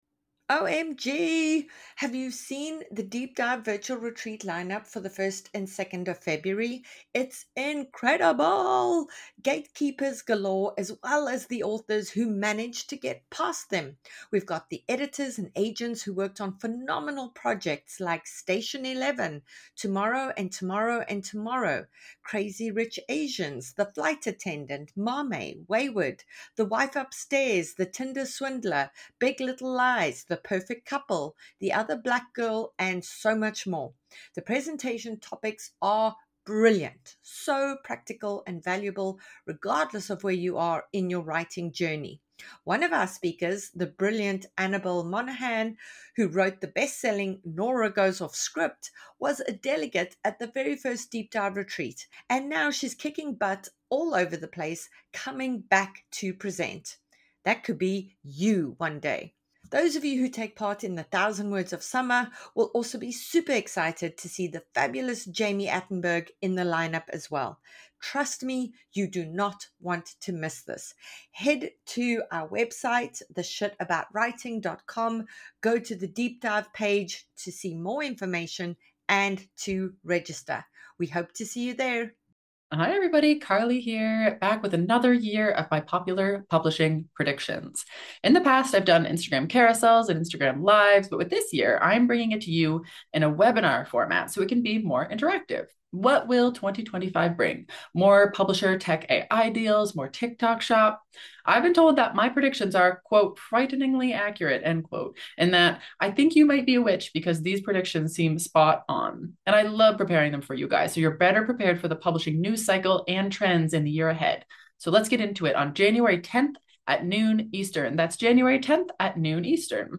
They discuss the importance of character development, plot clarity, and the balance between world-building and emotional engagement. The conversation emphasizes the need for specificity in storytelling and the challenges of writing historical and fantasy fiction.